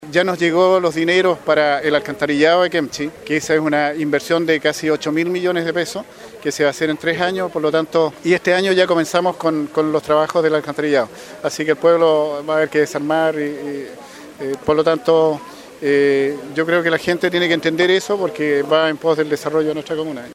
Gustavo Lobos, alcalde de Quemchi, confirmó que ya están en poder del municipio los recursos para materializar el alcantarillado.
CUÑA-1-GUSTAVO-LOBOS-.mp3